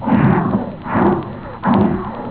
Monster1
MONSTER1.wav